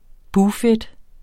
Udtale [ ˈbuː- ]